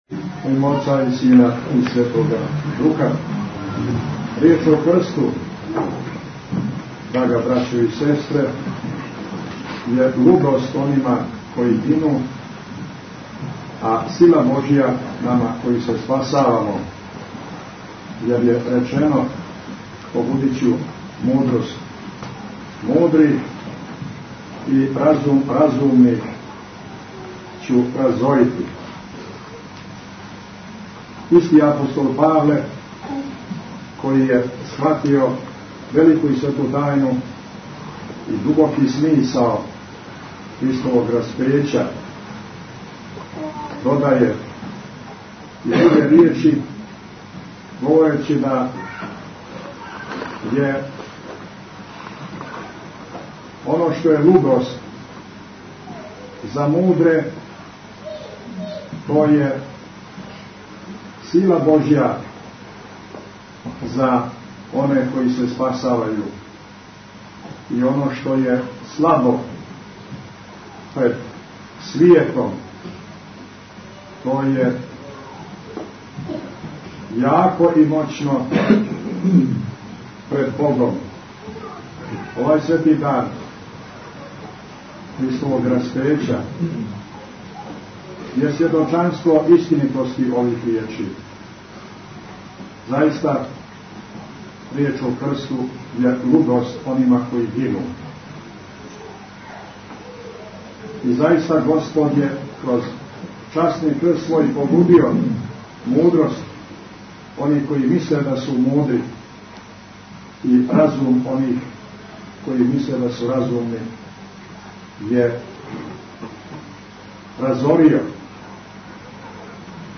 Бесједа Митрополита Амфилохија са Свете службе изношења плаштанице у Цетињском манастиру 6. априла 2007 | Радио Светигора
Бесједе